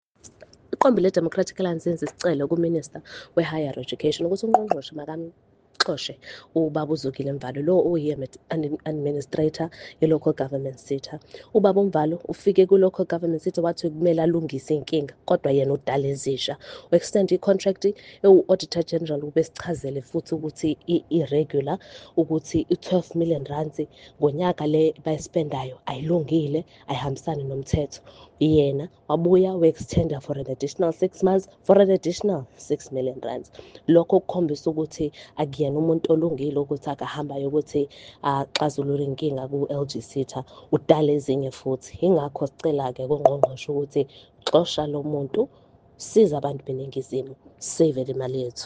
isiZulu soundbites by Karabo Khakhau MP.